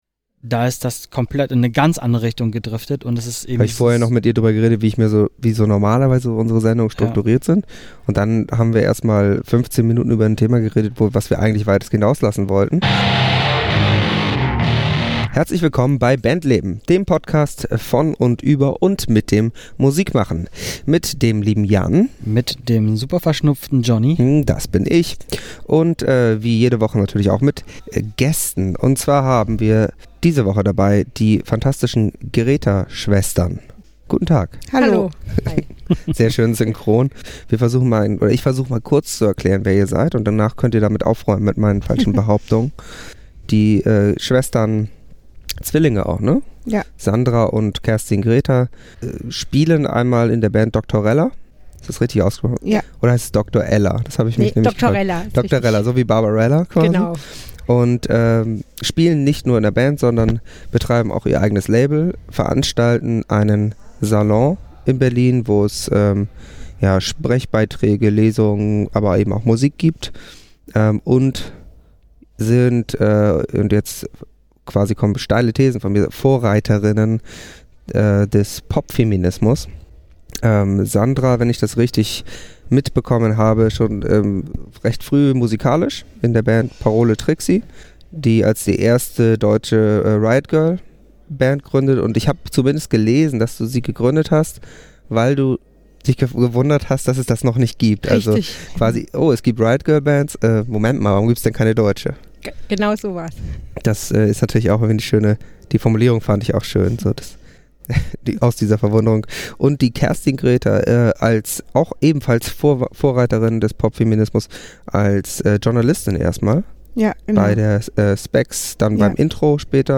um genau zu sein im Innenhof, was teilweise für etwas Lärm und nicht ganz optimale Qualität gesorgt hat, wo wir uns über Popfeminismus, Musikindustrie, den Berliner Wohnungsmarkt, Campiness und vieles mehr unterhalten haben.